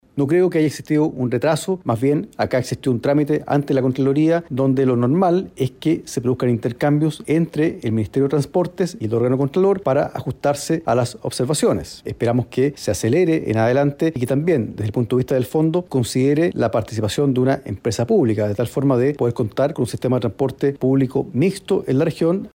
Luis Cuello, diputado comunista por la región de Valparaíso, remarcó que no hubo tardanza en el proceso, sino que se realizó un trabajo minucioso de observaciones.